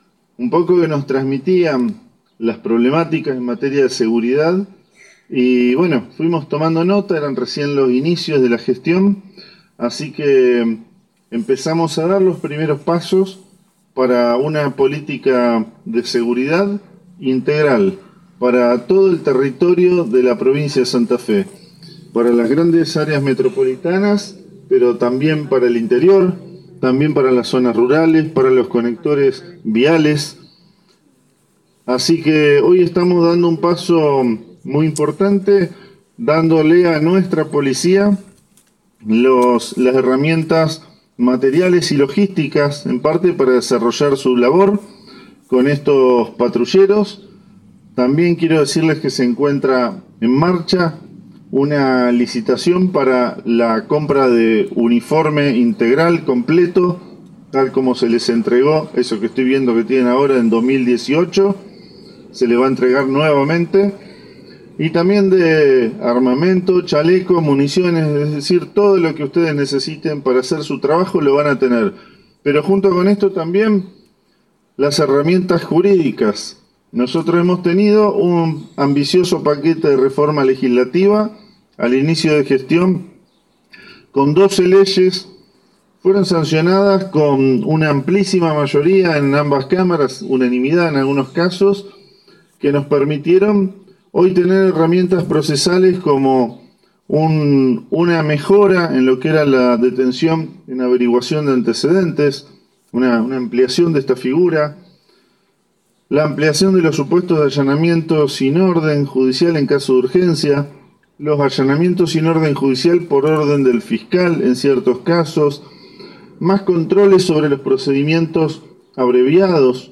Fue en un acto realizado en la U.R XIX que fue encabezado por los ministros, Pablo Cococcioni y Fabián Bastia.
Pablo Cococcioni – Ministro de Justicia y Seguridad de la provincia